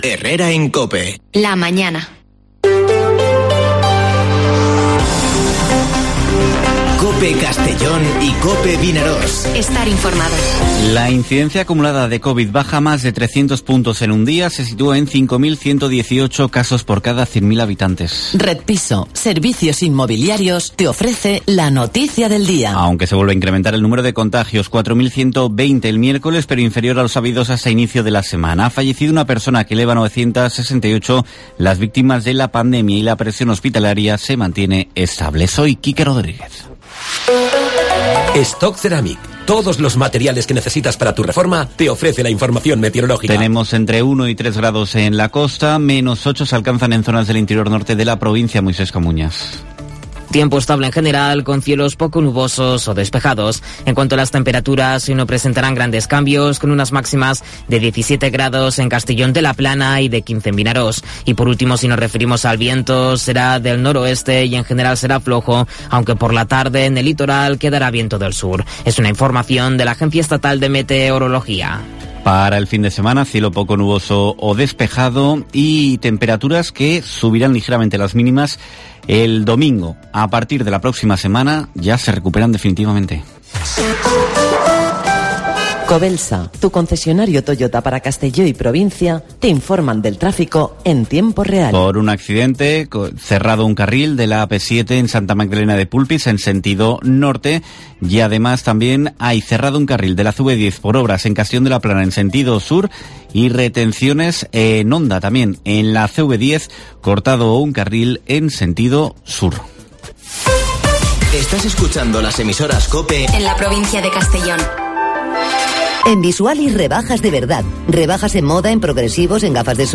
Informativo Herrera en COPE en la provincia de Castellón (28/01/2022)